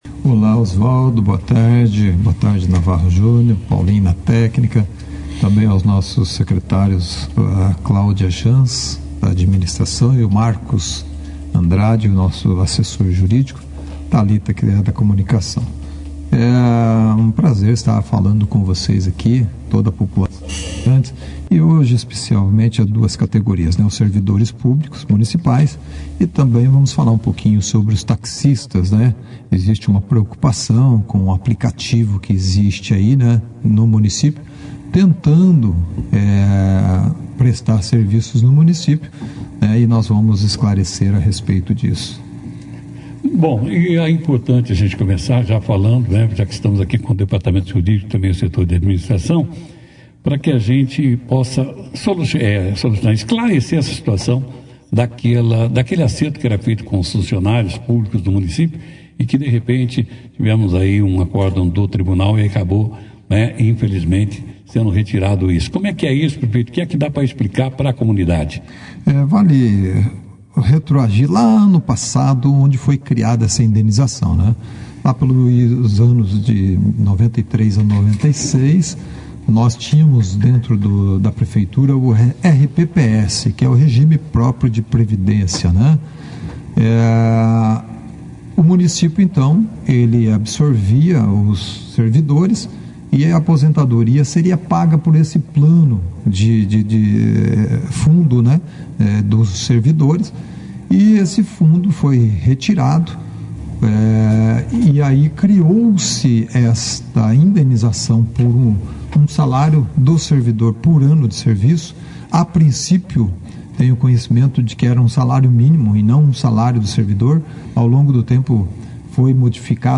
O prefeito de Bandeirantes, Jaelson Matta, participou da 2ª edição do Jornal Operação Cidade para esclarecer dúvidas dos servidores municipais sobre a Ação Direta de Inconstitucionalidade (ADI) que trata de dispositivos da lei municipal relacionados aos funcionários públicos.
Durante a entrevista, a equipe da administração municipal explicou os principais pontos da decisão judicial, os impactos para os servidores e os encaminhamentos que estão sendo avaliados pela prefeitura diante da decisão do Tribunal de Justiça. Outro assunto abordado foi a chegada de uma empresa de transporte por aplicativo que passou a operar na cidade sem autorização do município.